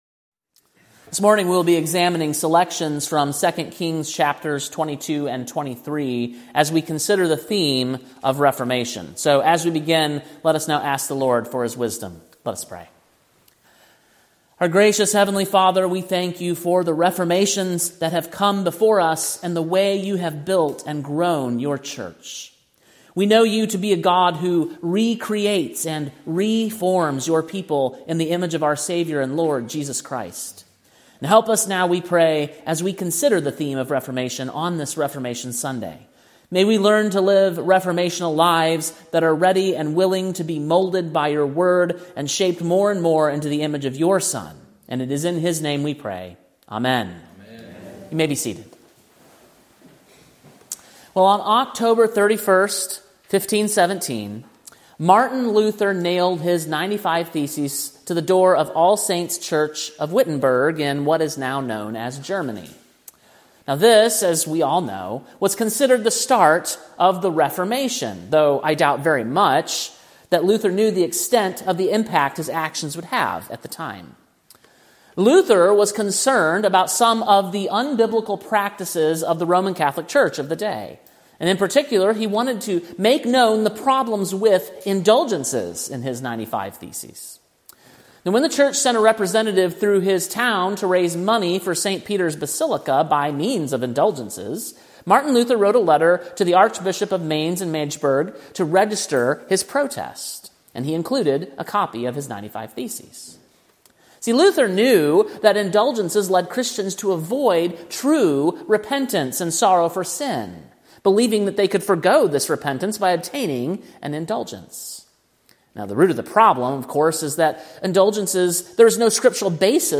Sermon preached on October 27, 2024, at King’s Cross Reformed, Columbia, TN.